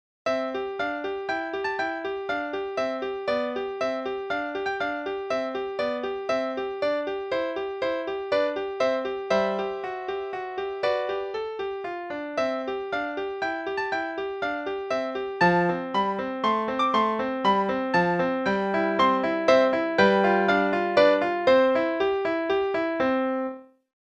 Czerny 13 (en=120).mp3